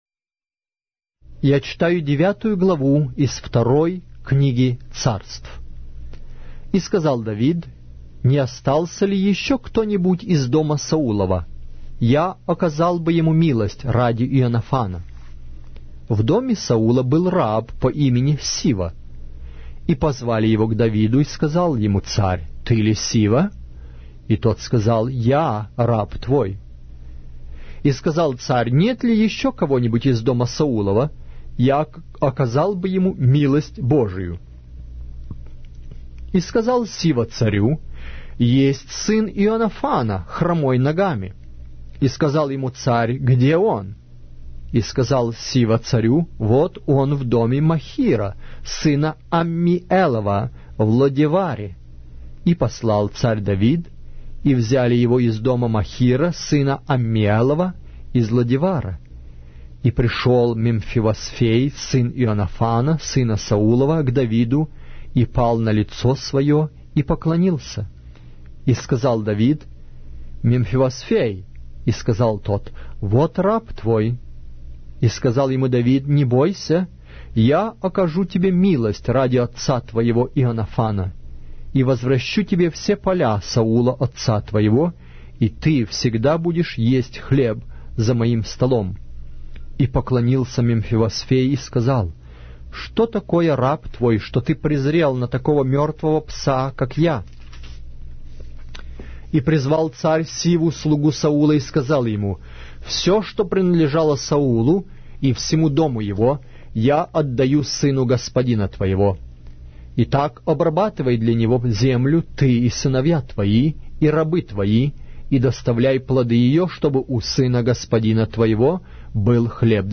Аудиокнига: 2-я Книга Царств